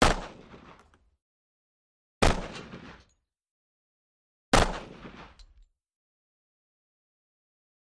.45 Caliber Pistol: Single Shots. Dual